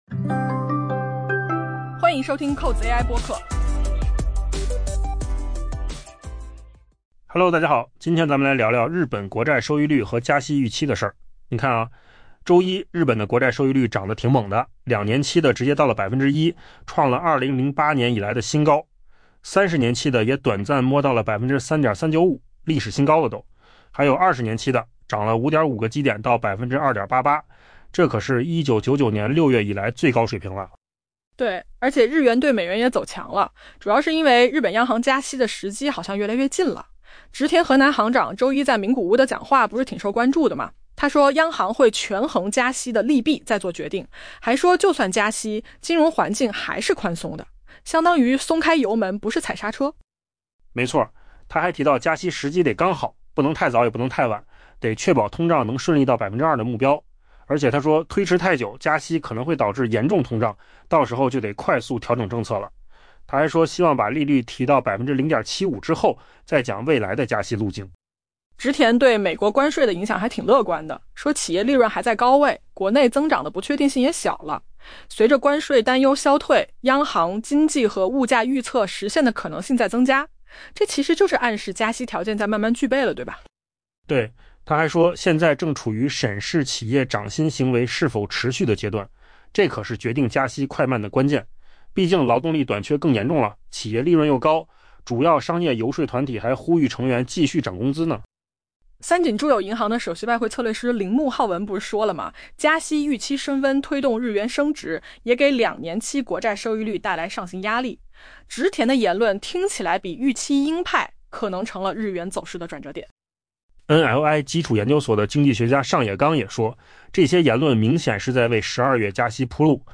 AI 播客：换个方式听新闻 下载 mp3 音频由扣子空间生成 周一，日本两年期国债收益率升至 1%，创 2008 年以来最高水平；日本 30 年期国债收益率短暂触及 3.395%，创历史新高；日本 20 年期国债收益率上涨 5.5 个基点，至 2.88%，创 1999 年 6 月以来最高水平。